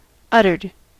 Ääntäminen
Ääntäminen US Haettu sana löytyi näillä lähdekielillä: englanti Uttered on sanan utter partisiipin perfekti.